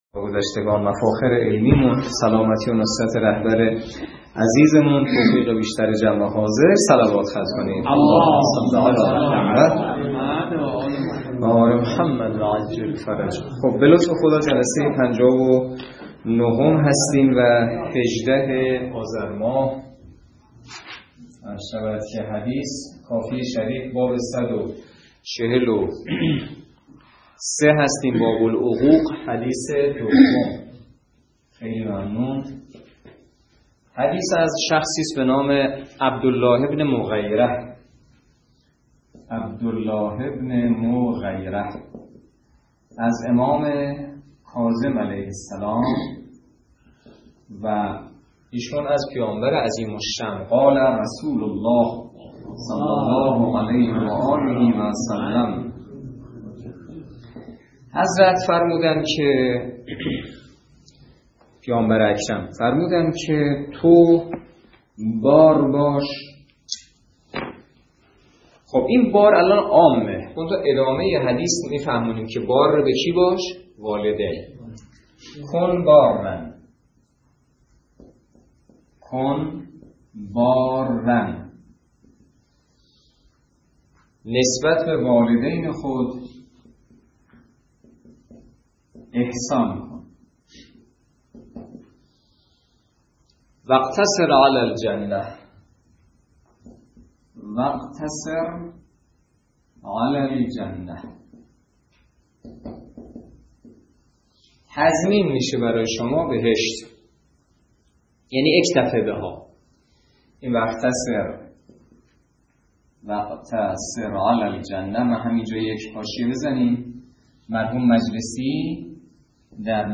درس فقه الاجاره نماینده مقام معظم رهبری در منطقه و امام جمعه کاشان - سال سوم جلسه پنجاه و نه